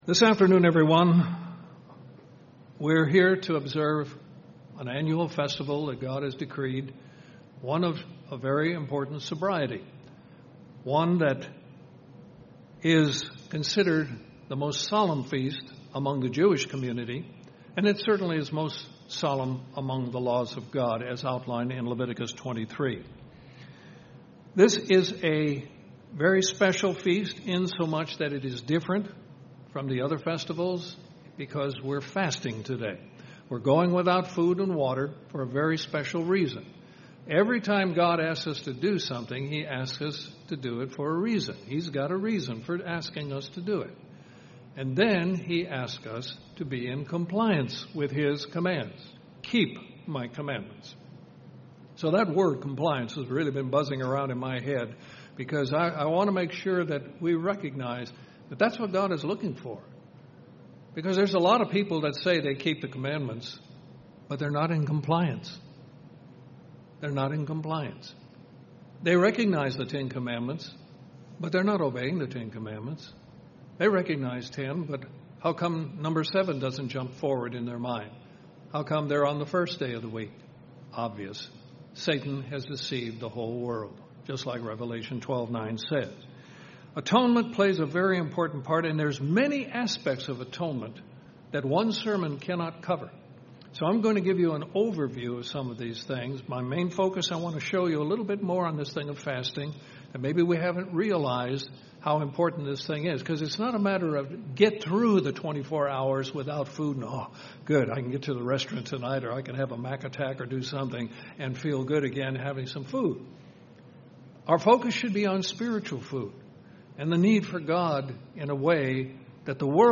This sermon is an overview of the Day of Atonement and the spiritual food we receive on this day of compliance and coverings.
Given in Columbus, GA Central Georgia